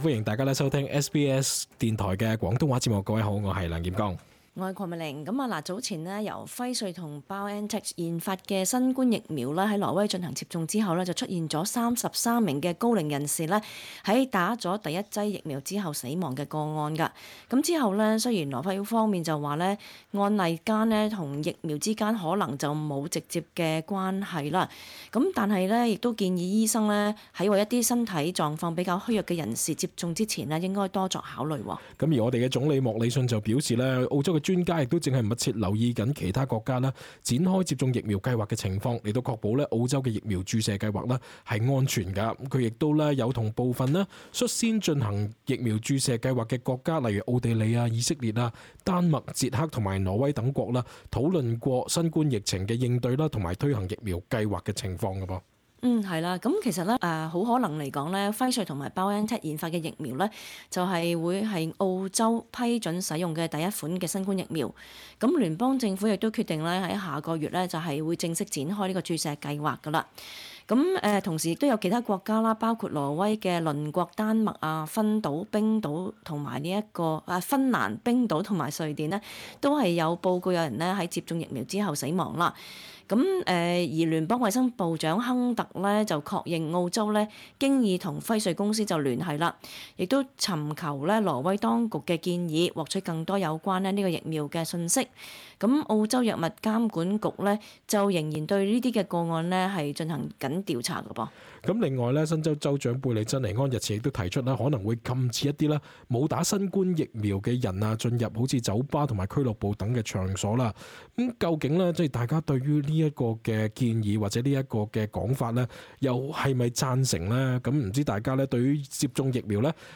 talkback_-_uploadjan_21-.mp3